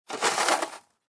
descargar sonido mp3 pala nieve 1